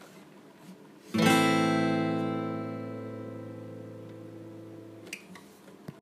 【ギターコード譜とコードの音】
Amコード